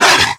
57e0746fe6 Divergent / mods / Soundscape Overhaul / gamedata / sounds / monsters / dog / attack_hit_3.ogg 8.5 KiB (Stored with Git LFS) Raw History
attack_hit_3.ogg